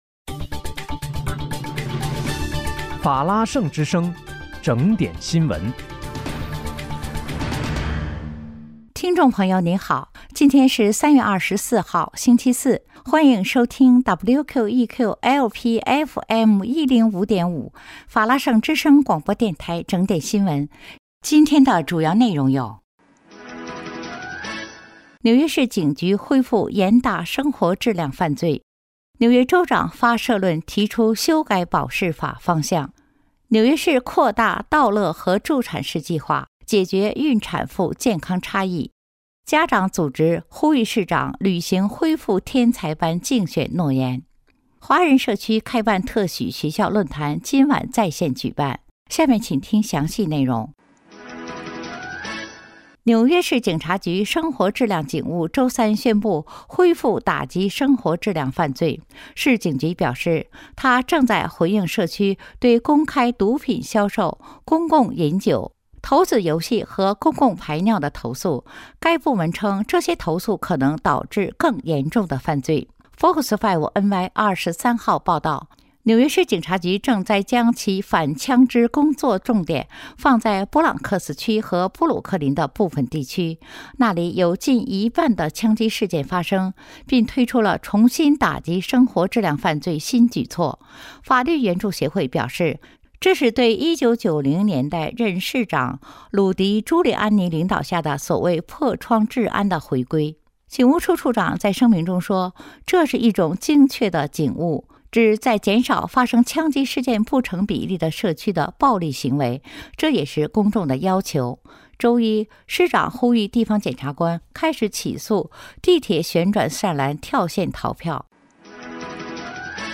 3月24日（星期四）纽约整点新闻